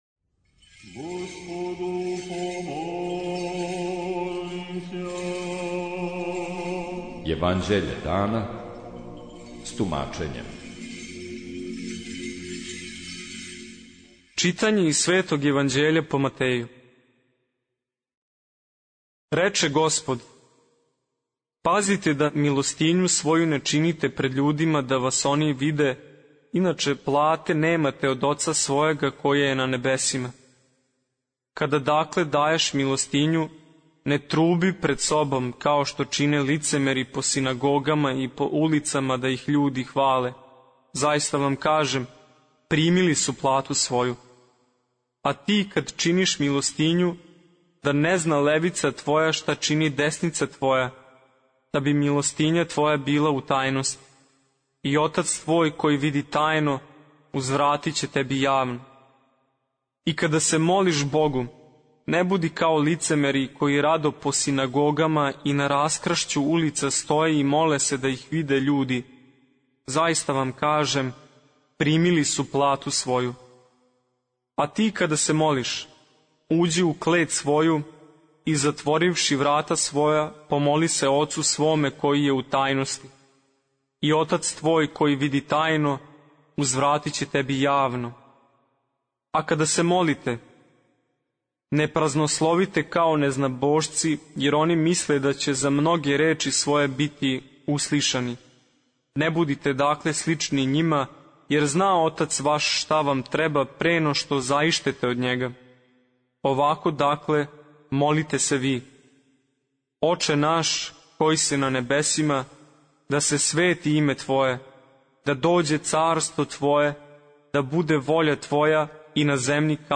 Читање Светог Јеванђеља по Луки за дан 12.03.2024. Зачало 109.